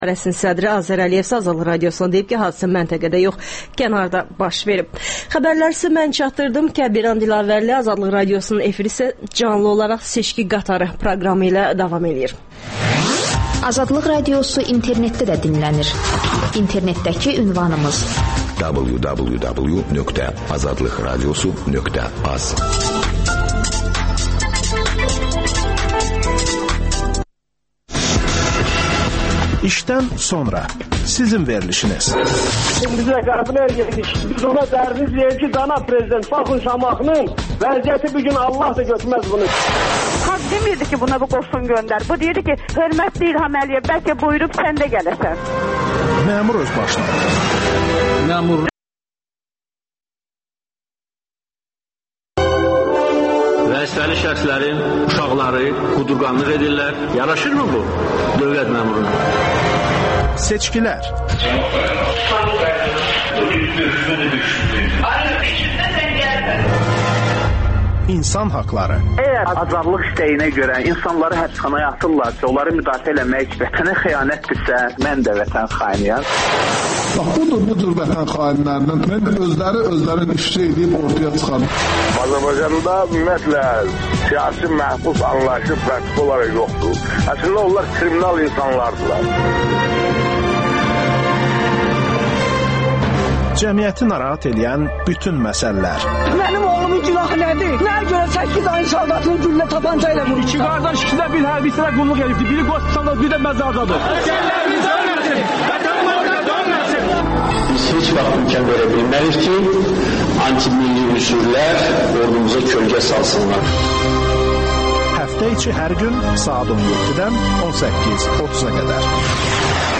AzadlıqRadiosunun müxbirləri məntəqə-məntəqə dolaşıb səsvermənin gedişini xəbərləyirlər.